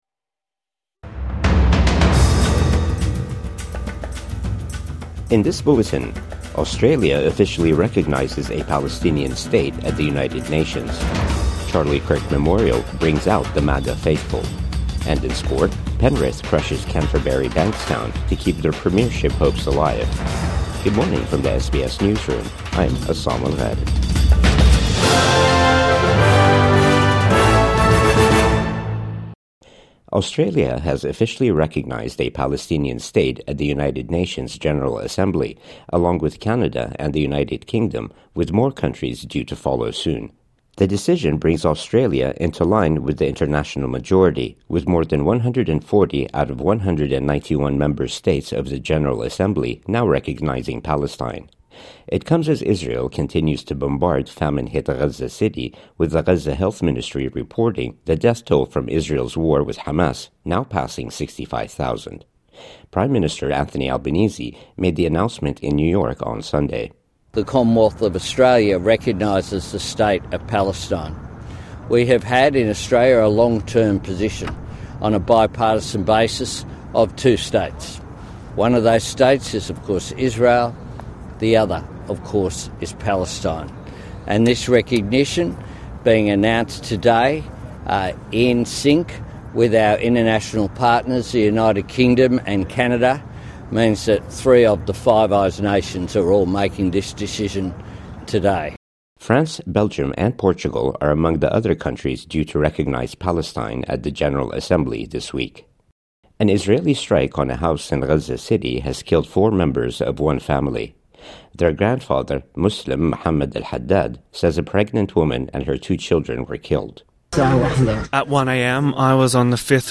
Australia officially recognises a Palestinian state at the UN | Morning News Bulletin 22 September 2025